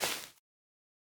Minecraft Version Minecraft Version snapshot Latest Release | Latest Snapshot snapshot / assets / minecraft / sounds / block / spore_blossom / step4.ogg Compare With Compare With Latest Release | Latest Snapshot
step4.ogg